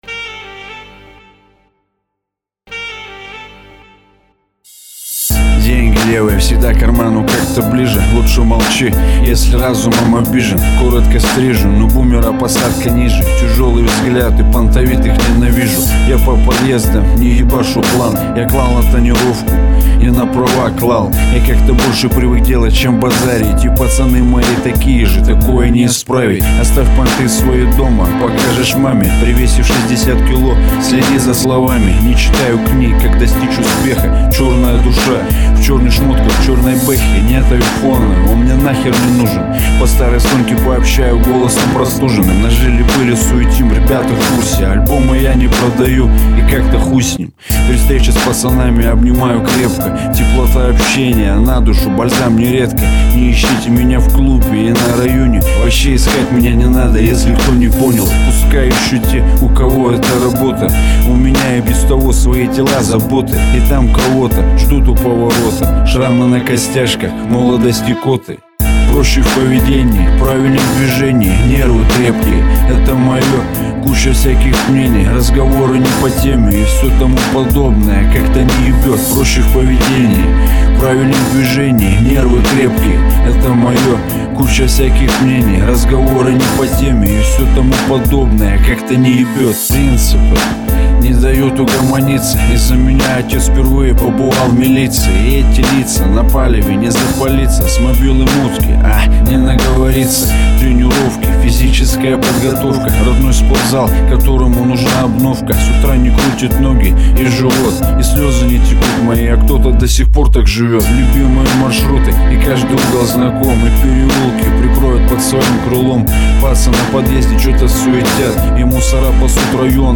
Жанр:Рэп